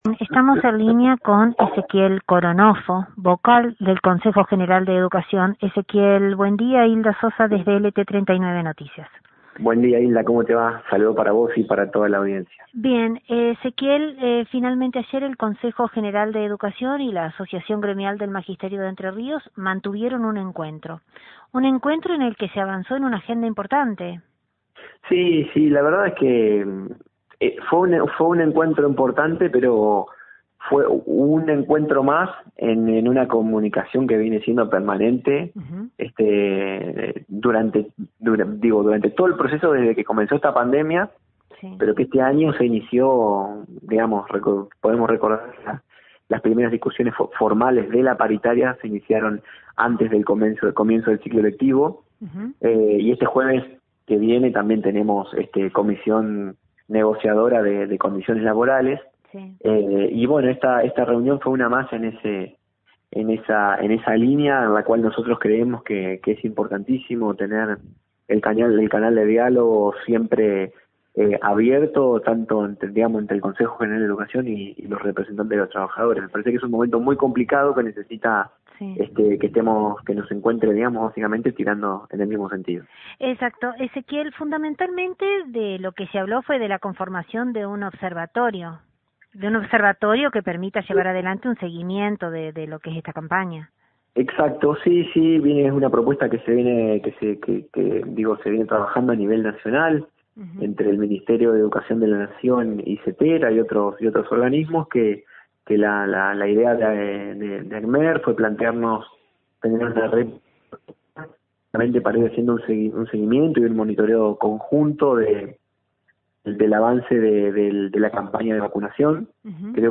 Después de la reunión mantenida entre representantes del Consejo General de Educación y de la Asociación Gremial de Entre Ríos, desde nuestro medio, dialogamos con Ezequiel Coronoffo, Vocal del CGE y partícipe de la misma.